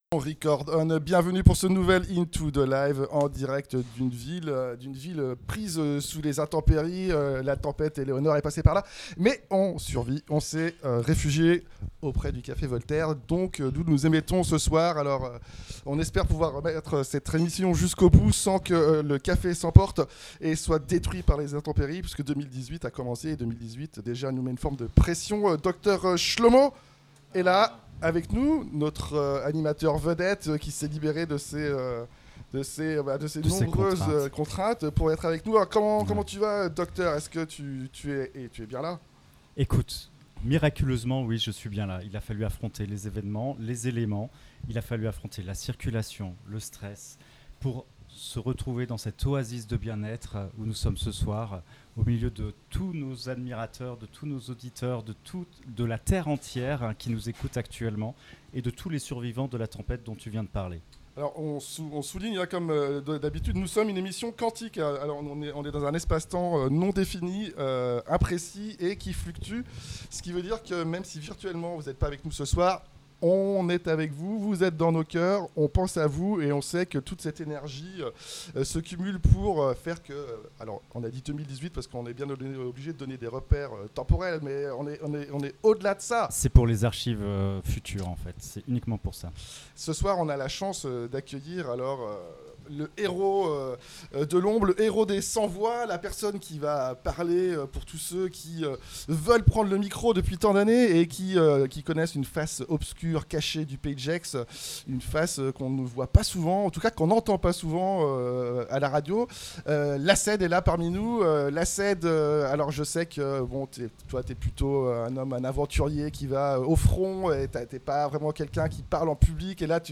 Frexit et nuances de 2018 en musique Live depuis le café voltaire, en mini public au carrefour des destins croisés des habitants du pays de Gex loin de Davos mais connectés au destin de la planète earth, et des chaussures de theresa May. Du moment present sur le fil de l'impro, 35 mn pour cette première partie.